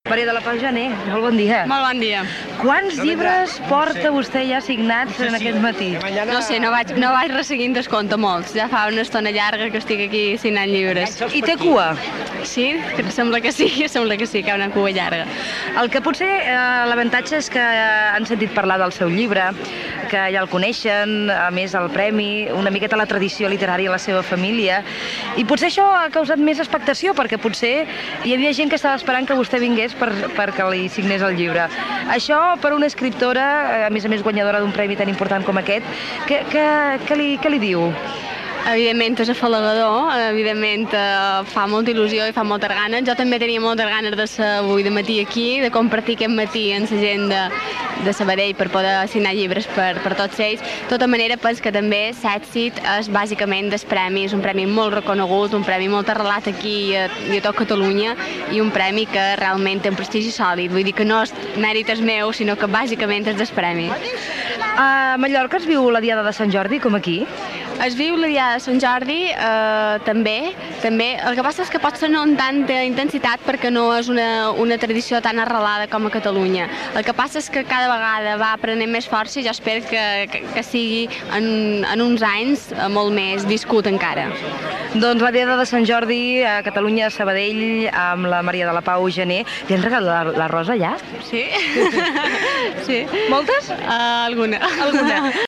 Entrevista a l'escriptora Maria de la Pau Janer, el dia de Sant Jordi en el moment de la signatura de llibres a la ciutat de Sabadell